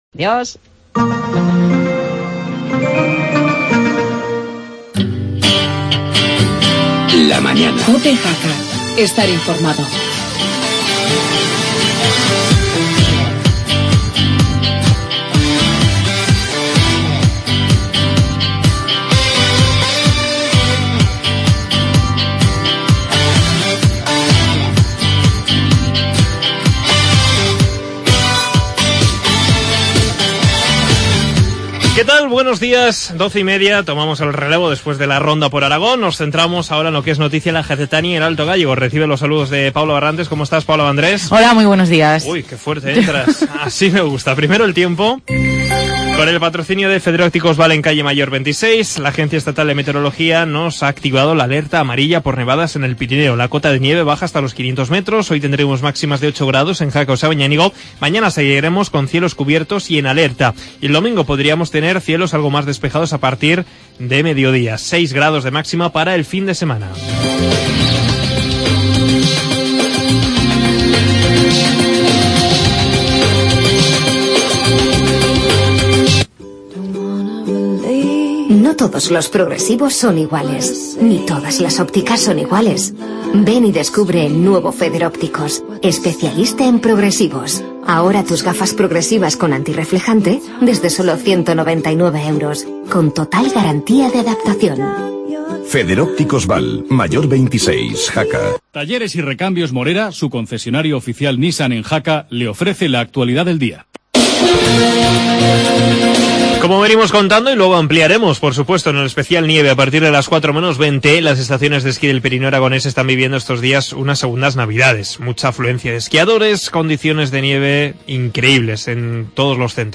Actualidad de Jaca y entrevista